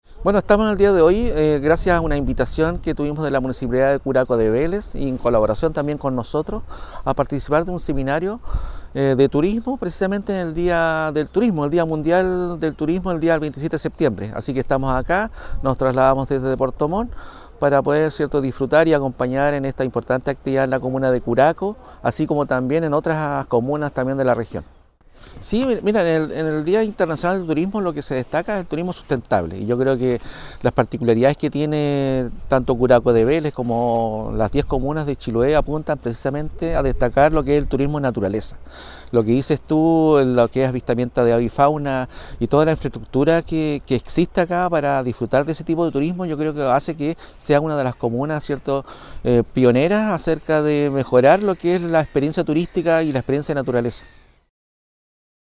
En ese mismo sentido, el director (s) de Sernatur Los Lagos, Luis Hurtado, indicó: